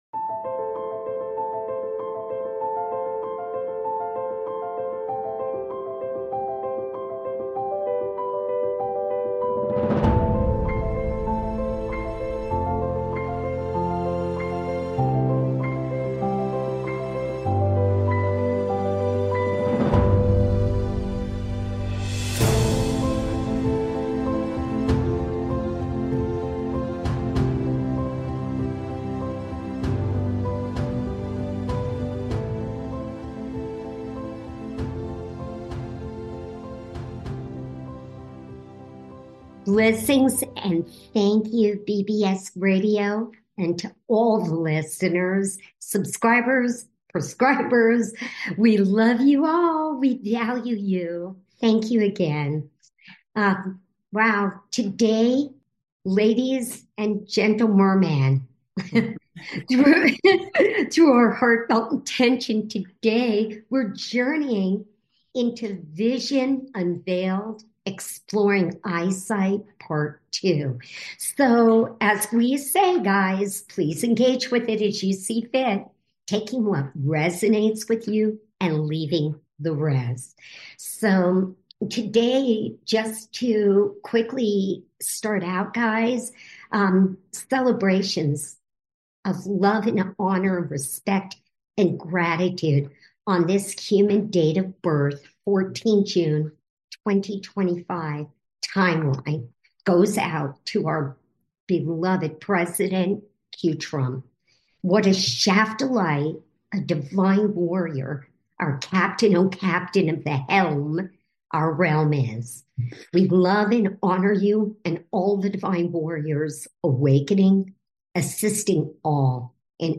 Occasionally, we'll also take "call-ins" and conduct "one-on-one" interviews.